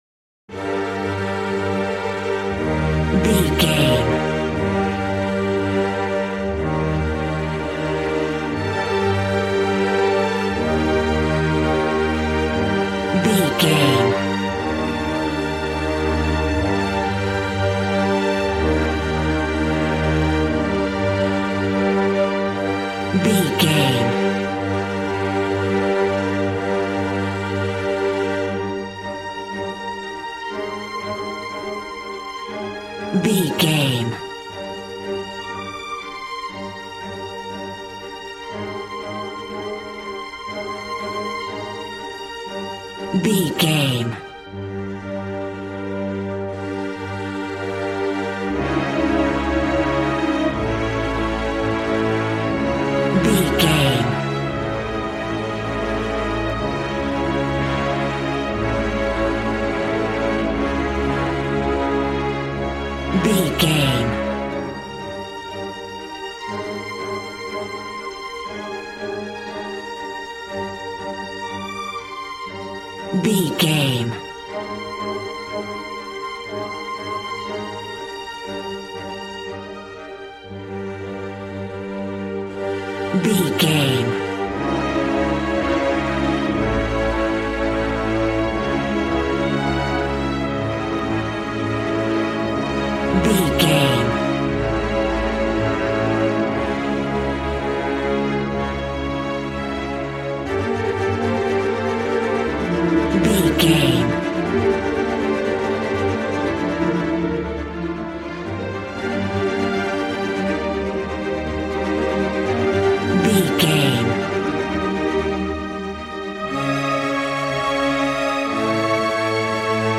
A classical music mood from the orchestra.
Regal and romantic, a classy piece of classical music.
Ionian/Major
B♭
regal
cello
violin
strings